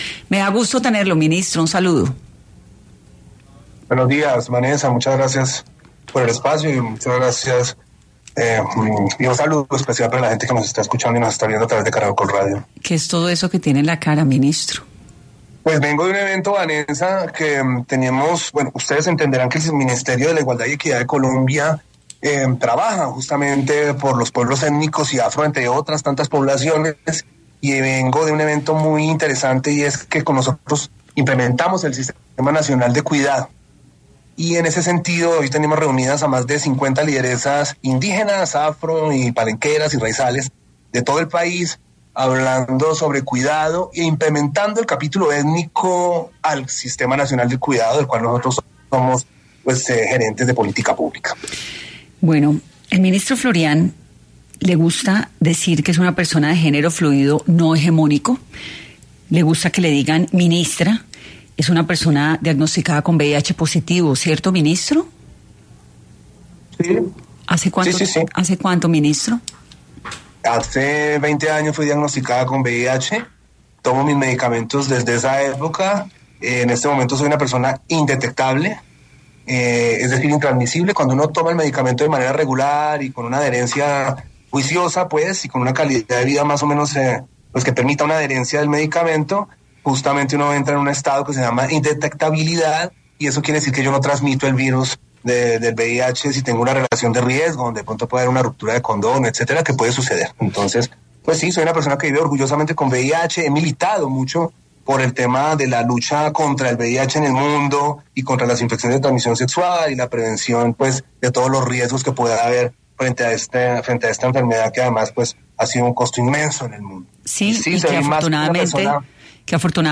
Juan Carlos Florián, habló en 10AM sobre los retos que ha asumido en estos dos meses en medio de las polémicas.